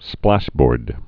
(splăshbôrd)